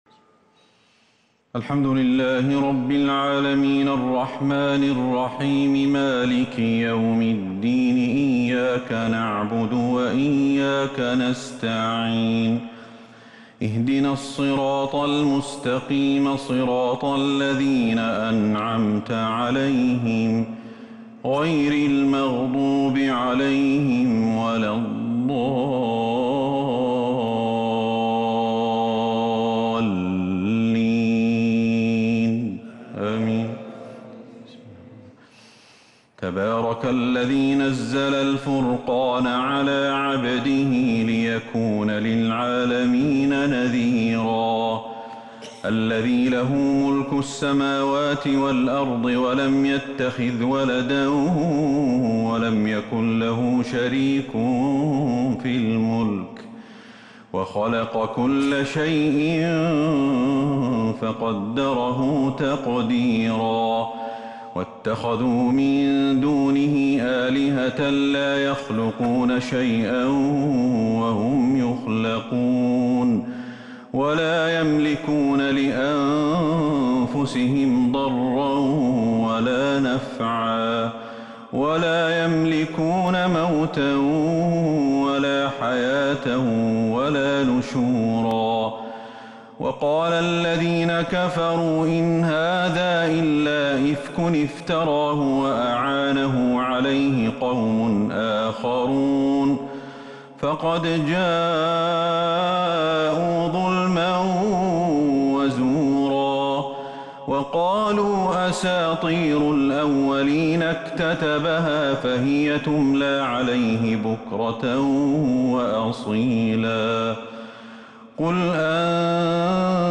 صلاة التهجد l ليلة 22 رمضان 1442 l سورة الفرقان Tahajjud prayer The night of Ramadan 22 1442H > تراويح الحرم النبوي عام 1442 🕌 > التراويح - تلاوات الحرمين